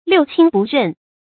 成語注音ㄌㄧㄨˋ ㄑㄧㄣ ㄅㄨˋ ㄖㄣˋ
成語拼音liù qīn bù rèn
六親不認發音
成語正音親，不能讀作“qìnɡ”。